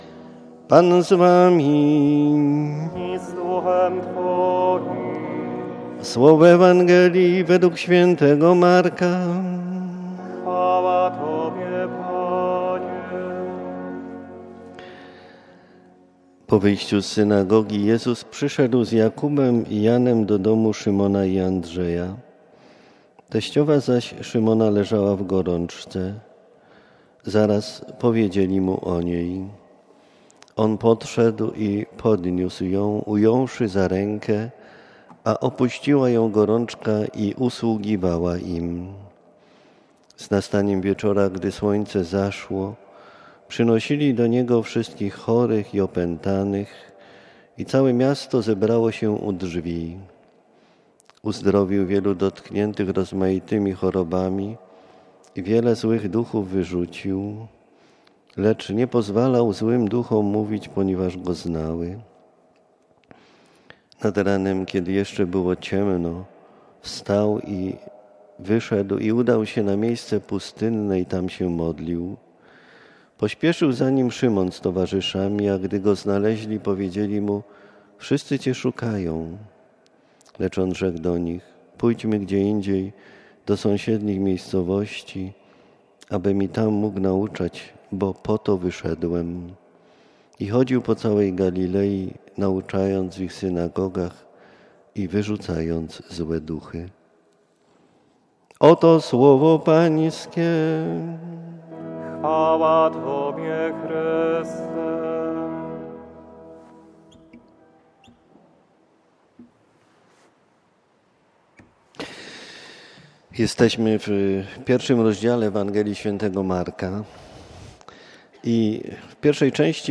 18:30 Msza Święta w kościele, po Mszy spotkanie zakończone dzieleniem w małych grupach,
“Homilia”